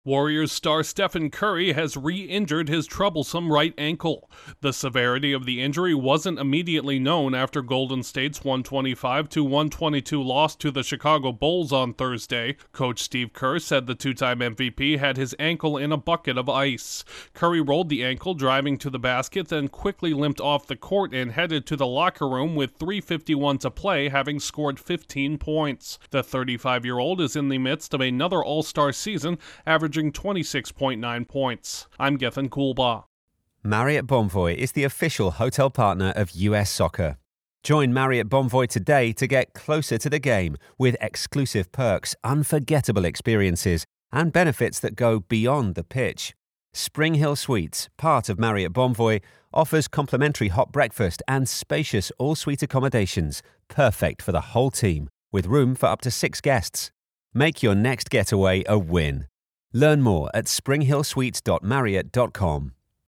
reports on a four-time NBA champion who's dealing with an injury.